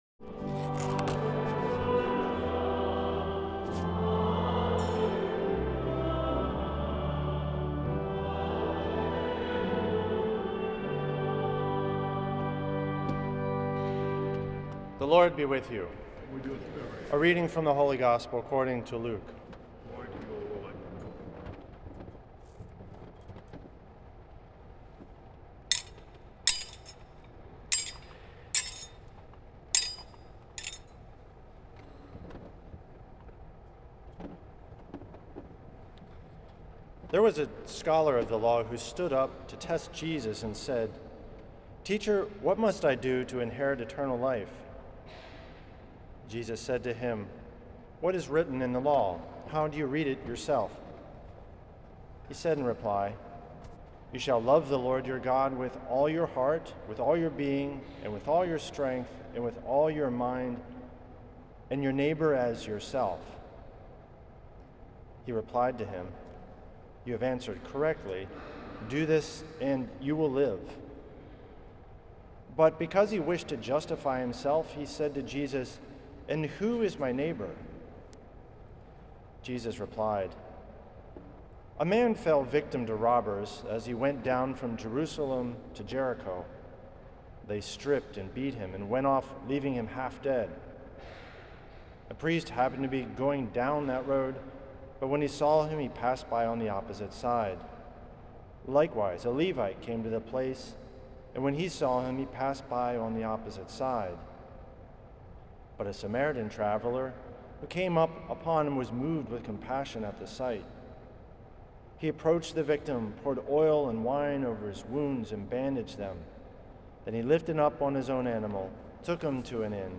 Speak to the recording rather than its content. at St. Patrick’s Old Cathedral in NYC on July 13th 2025.